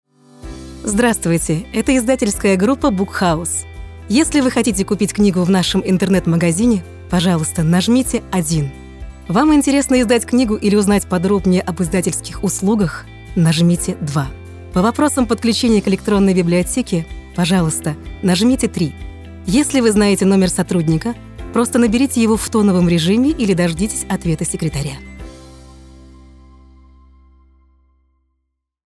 Обладательница лирико-драматического сопрано с диапазон более четырёх октав.
IVR